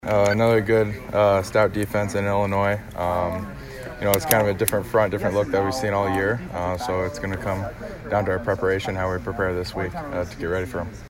Iowa junior center Tyler Linderbaum.